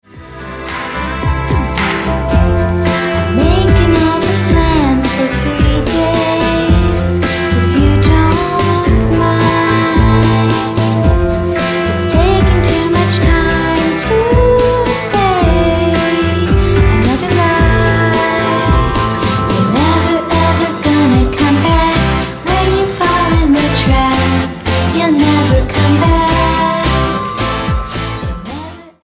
STYLE: Rock
uplifting music